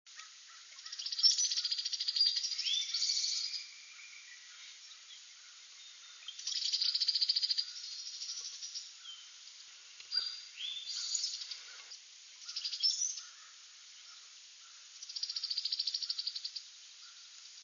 Cowbird
cowbirds_courting_short532.wav